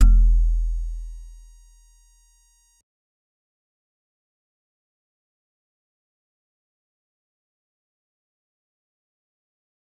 G_Musicbox-E1-pp.wav